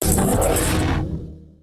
attack3.ogg